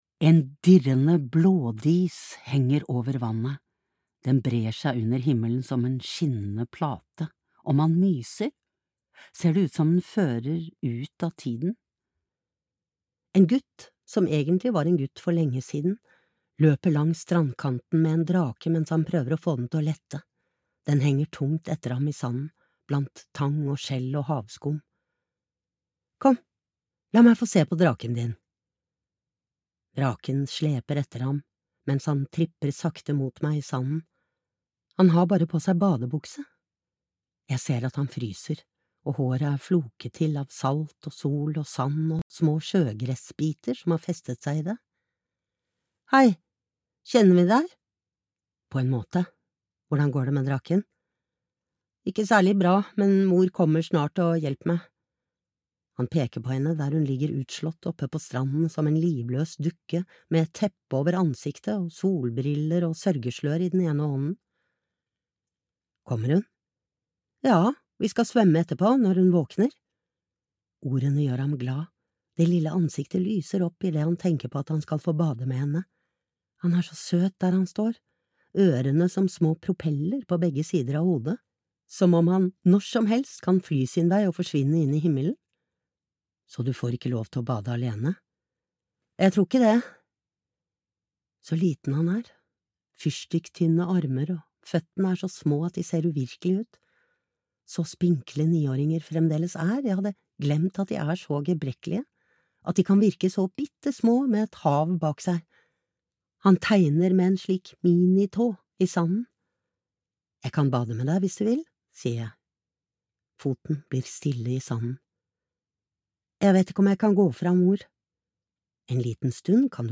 Farvel til Panic Beach (lydbok) av Sara Stridsberg